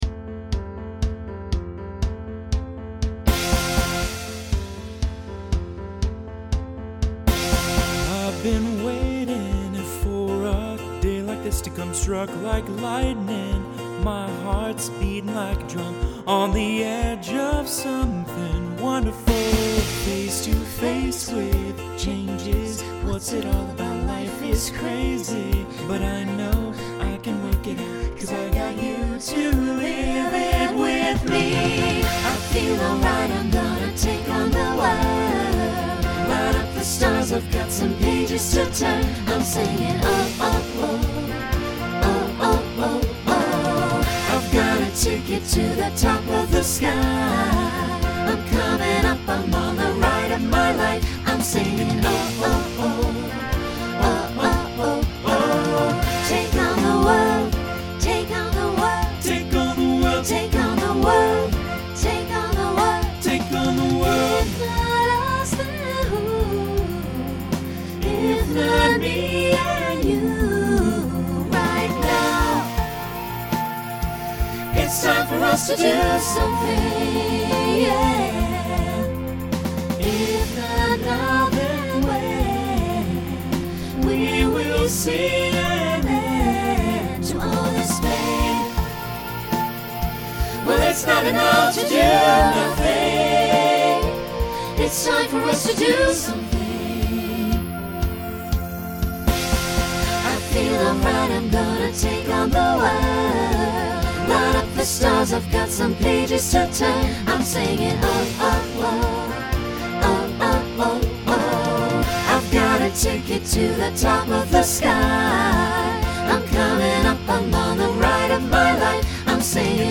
SATB Instrumental combo
Pop/Dance , Rock